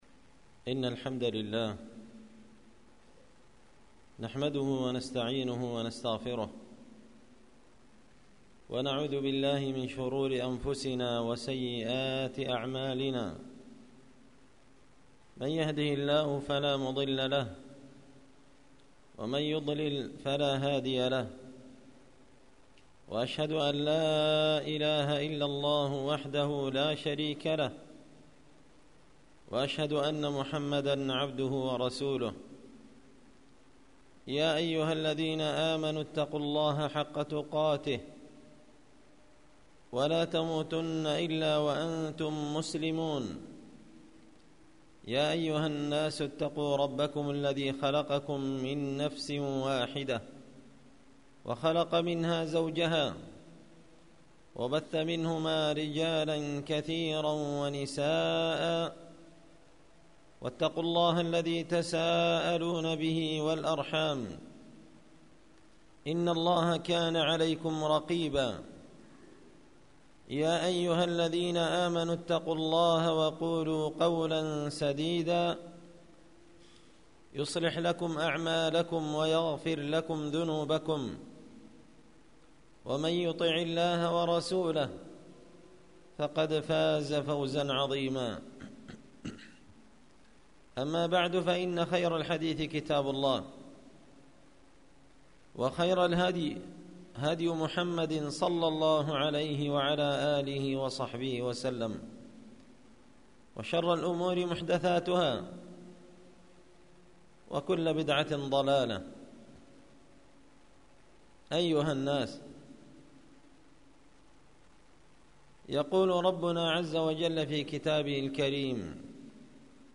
خطبة جمعة بعنوان تحذير الداني والقاصي من آثار الذنوب والمعاصي